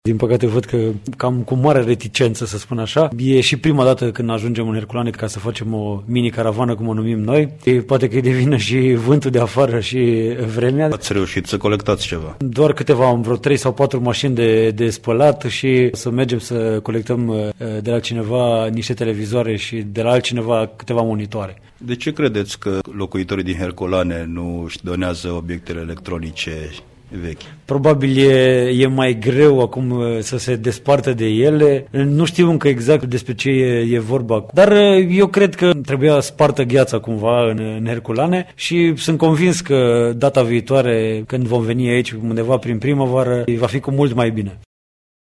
declarația